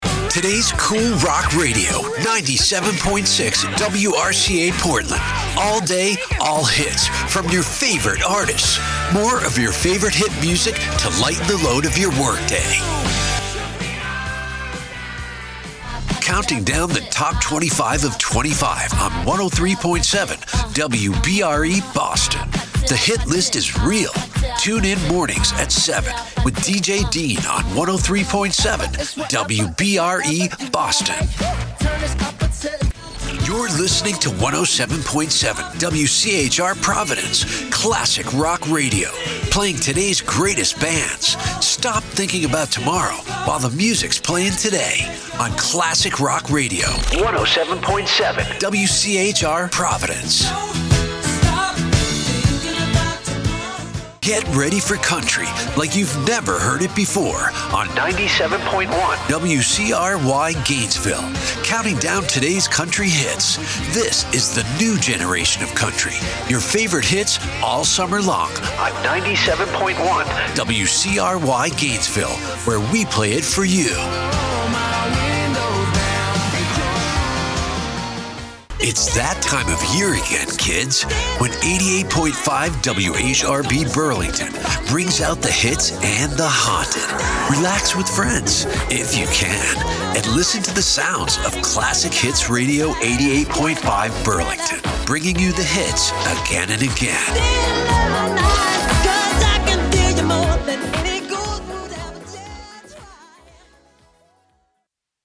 Imaging Demo